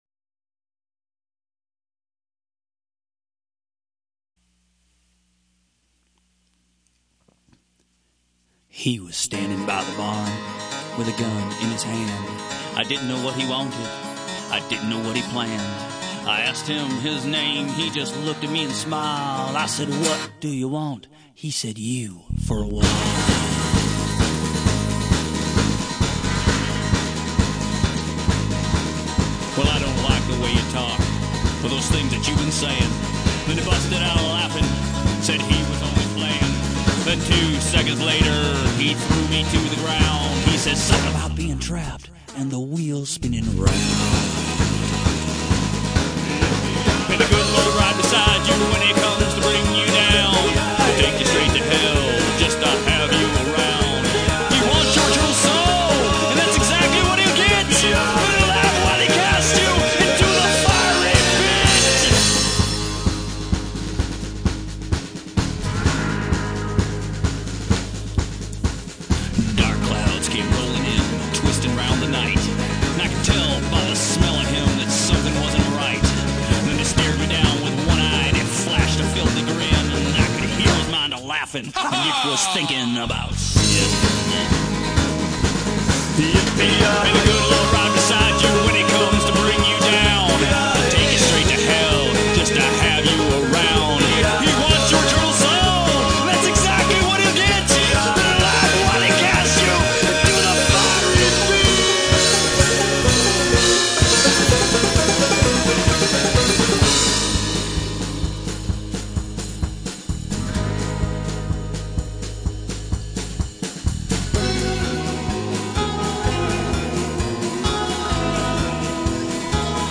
Hellfire and Brimstone Psychadelic Cowpunk